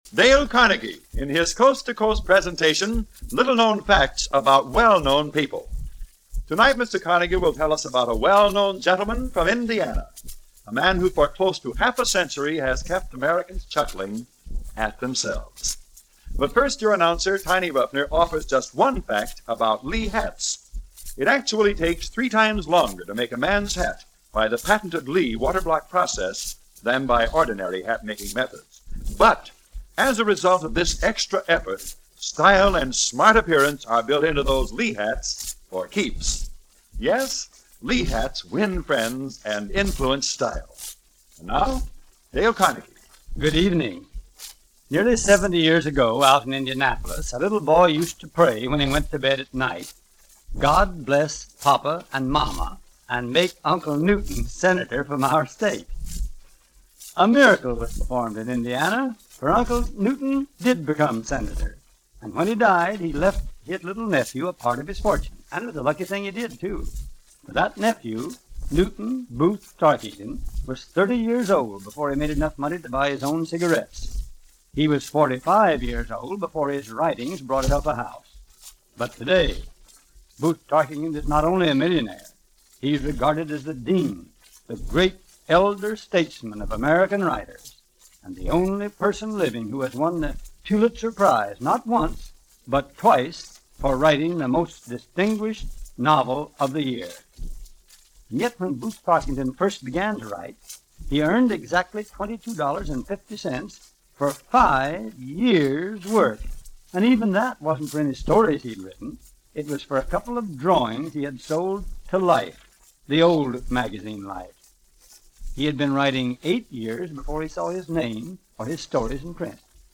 Booth Tarkington – a name that most likely rings no bells of recognition, outside of those studying 19th century/early 20th century American authors, discussed in a radio program narrated by the man who practically invented the Self-help book.